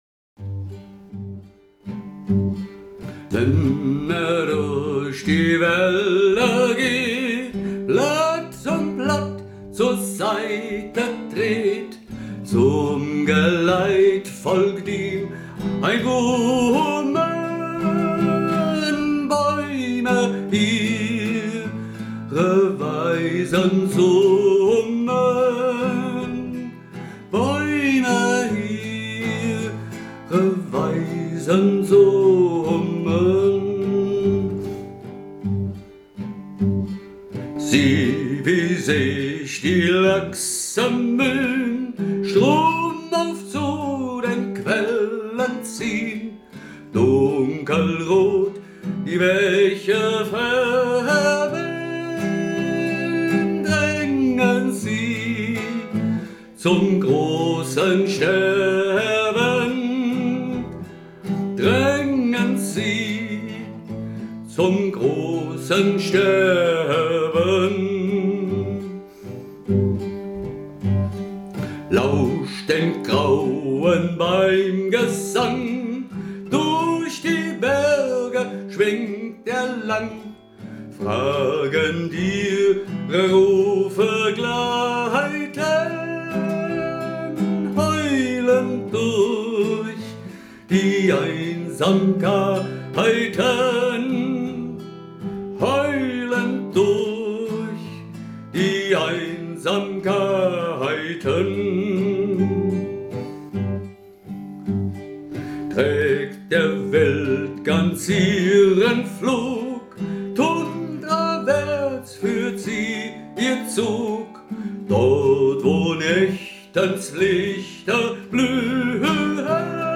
Klagend